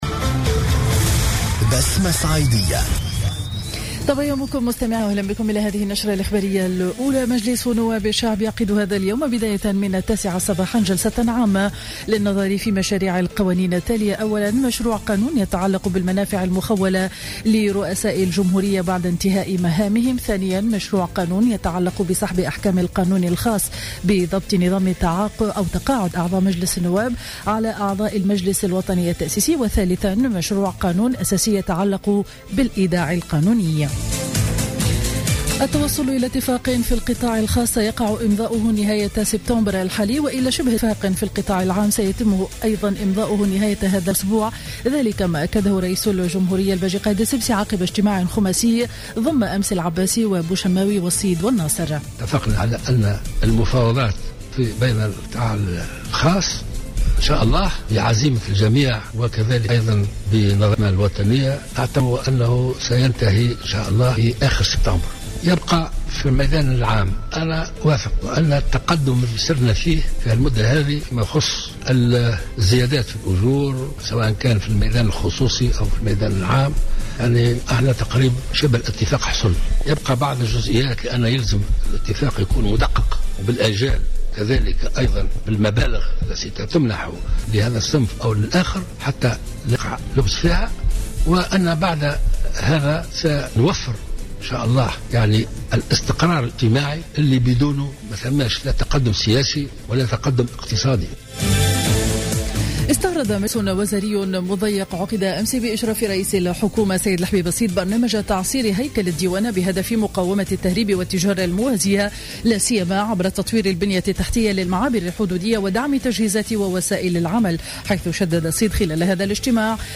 نشرة أخبار السابعة صباحا ليوم الثلاثاء 8 سبتمبر 2015